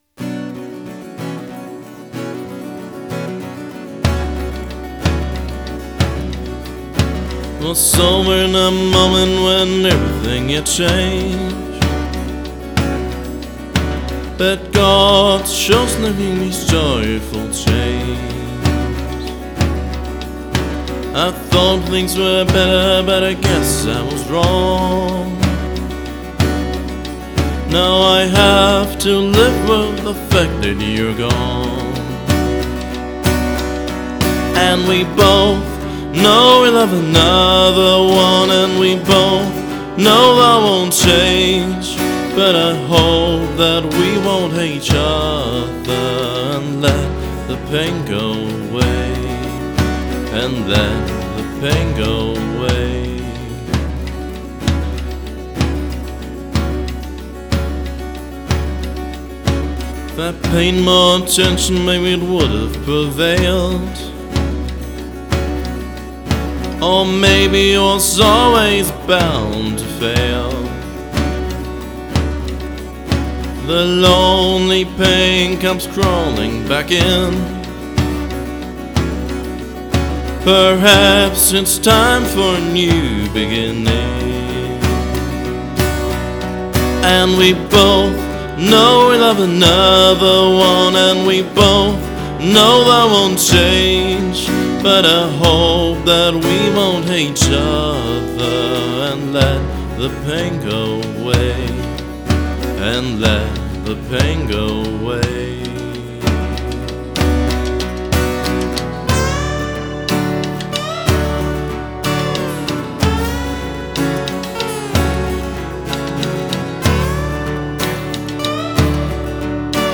(RockFolkCountry)